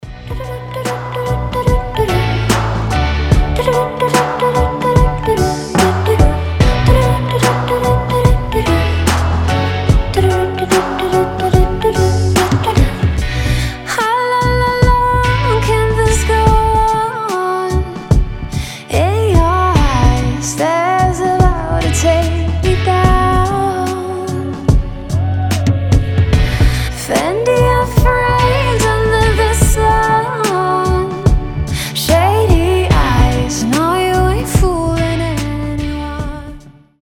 • Качество: 320, Stereo
приятные
indie pop
озорные